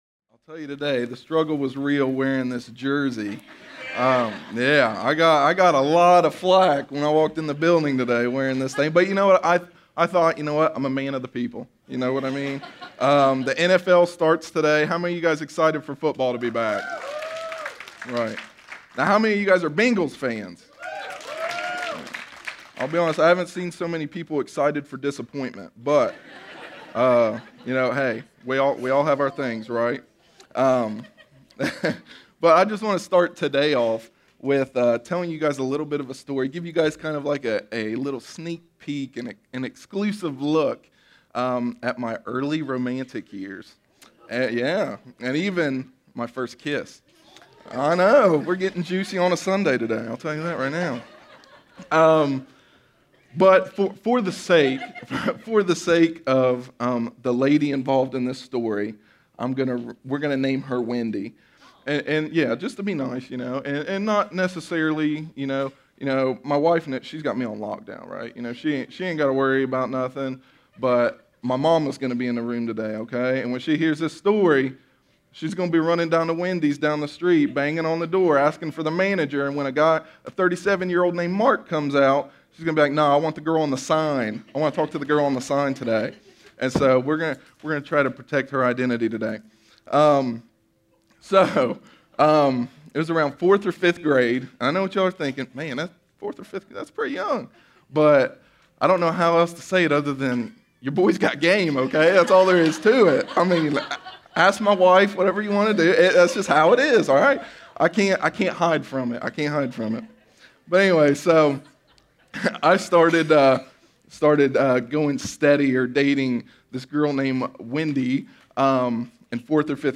A sermon from the series “The Struggle Is Real.”…